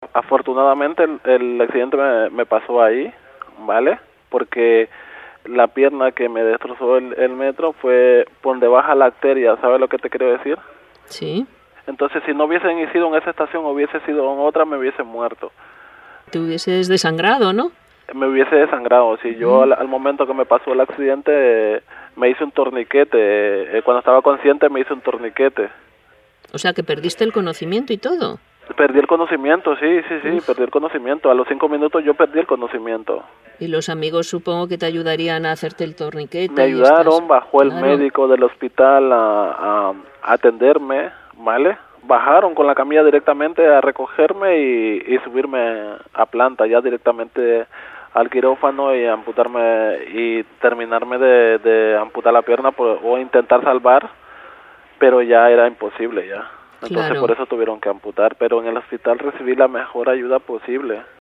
con detalle formato MP3 audio(1,17 MB).